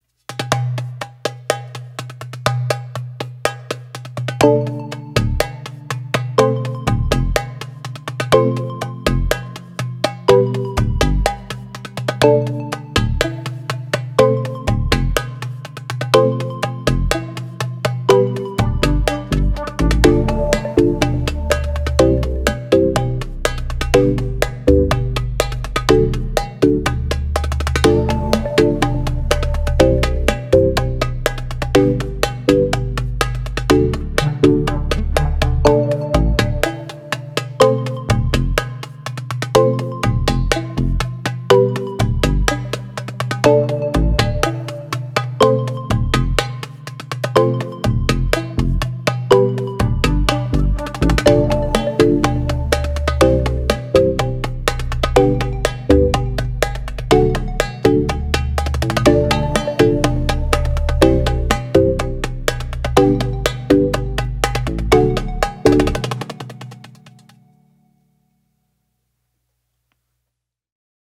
MEINL Percussion Aluminum Series Doumbek - 8 1/2" (HE-3000)
Handmade entirely of aluminum, the MEINL Doumbek is outstanding in sound and quality, reaching from resonant lows to ringing highs.